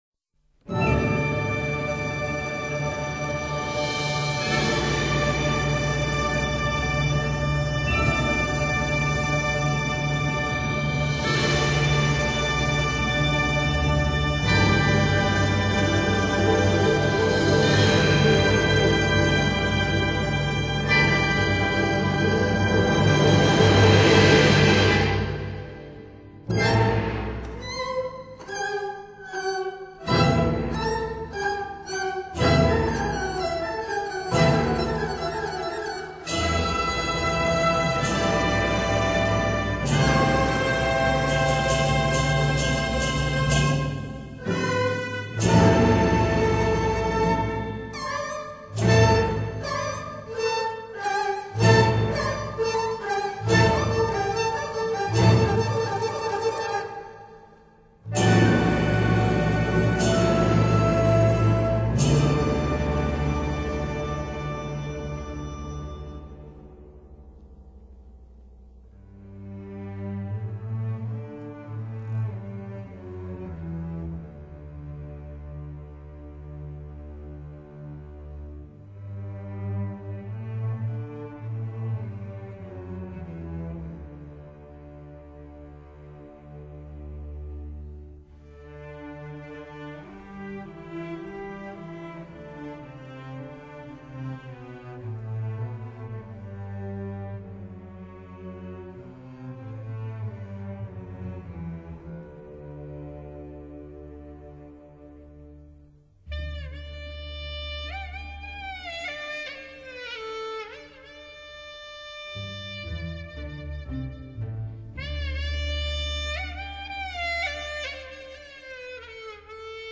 回荡着悲怆的音调、洋溢着炽烈的盛情，触发内心深处的感动，引领人们进入深思长考的境地。